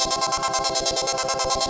SaS_MovingPad02_140-C.wav